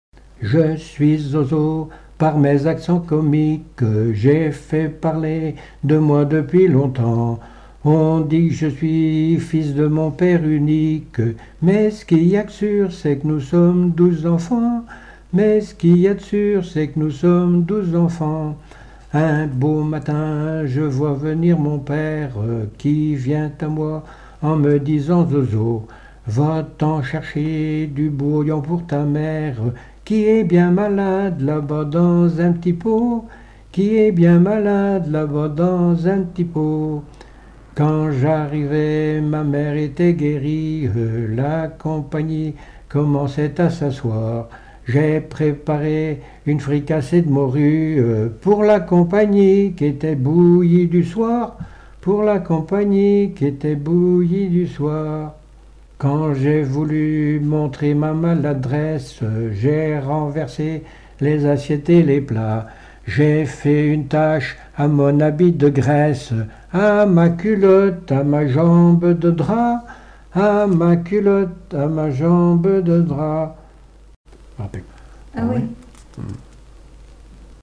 circonstance : éducation scolaire
Genre strophique
Pièce musicale inédite